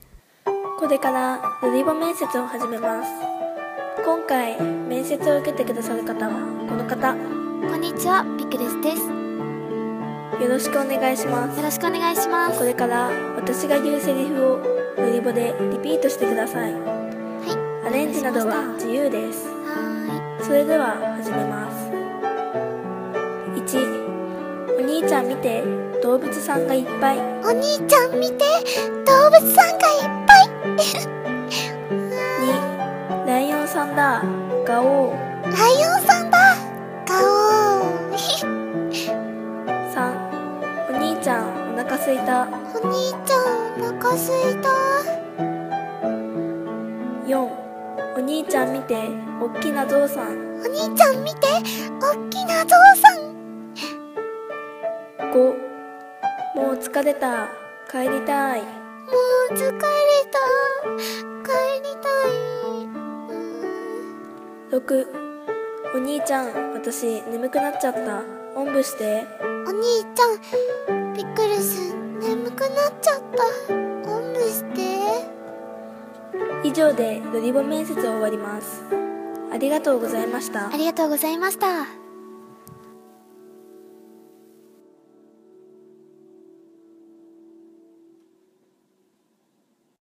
ロリボ面接 Part２